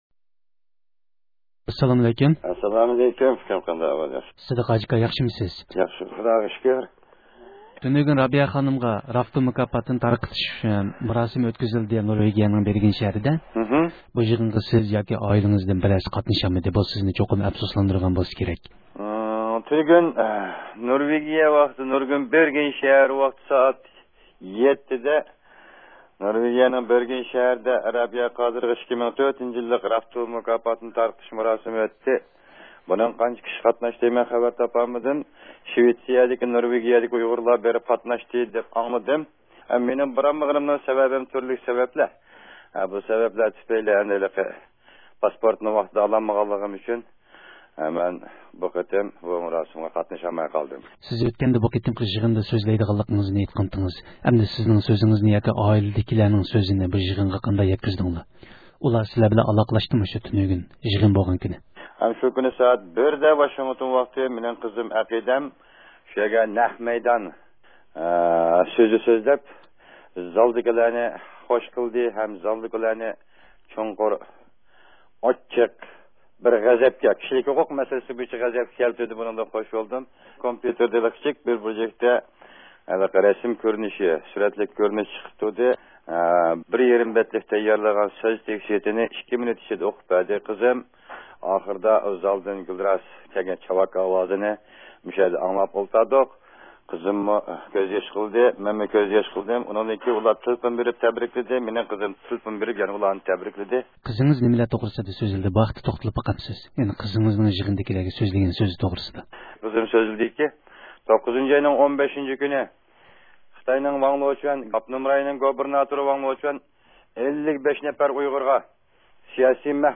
سۆھبەت